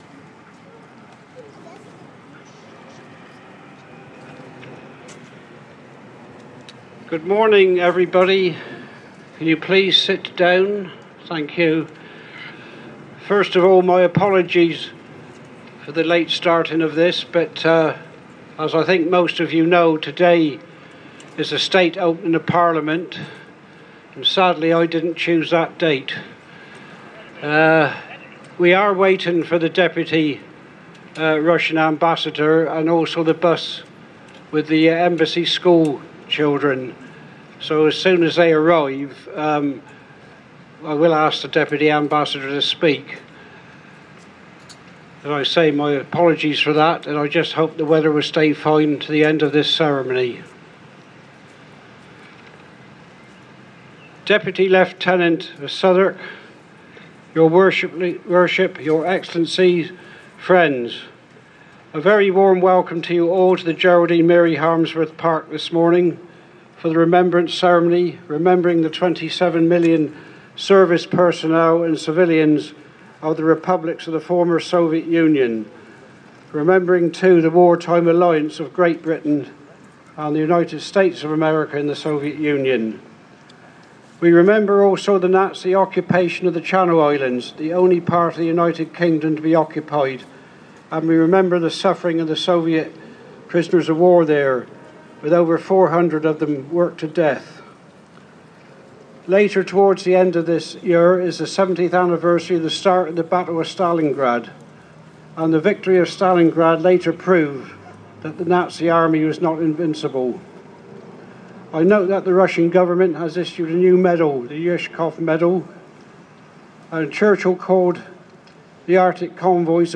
Victory Day 2012 at Soviet War Memorial